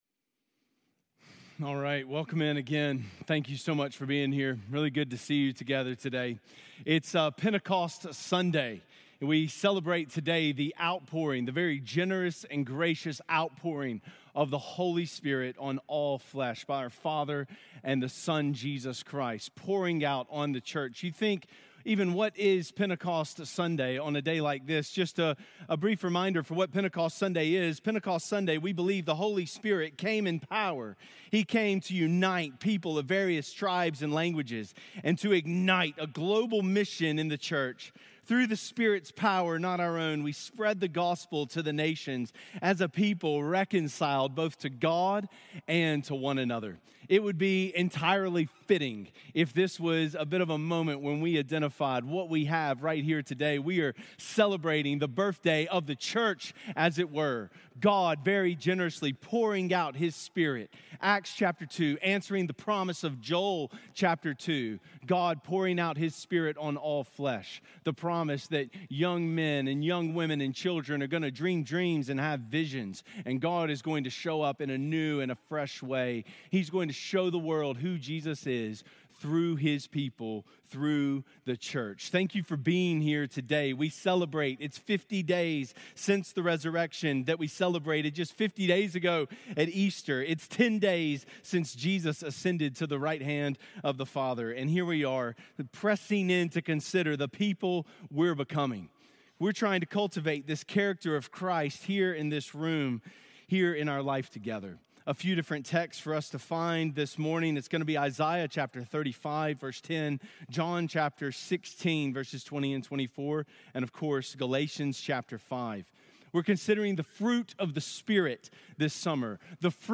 We're exploring the Fruit of the Spirit today, with a focus on "Joy." We hope that you encounter the joy of Jesus today in the music, in the message, and in the presence of this community.